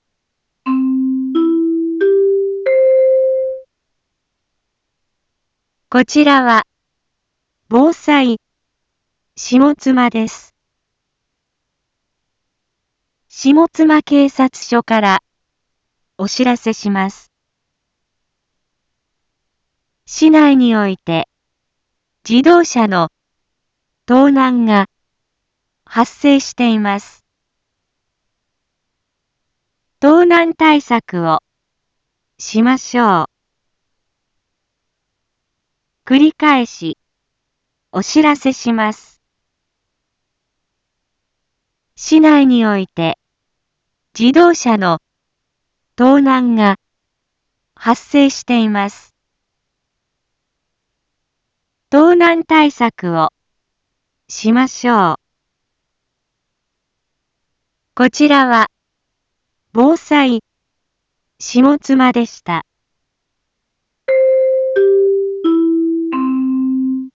一般放送情報
Back Home 一般放送情報 音声放送 再生 一般放送情報 登録日時：2021-12-10 12:31:11 タイトル：自動車盗難への警戒について インフォメーション：こちらは、防災下妻です。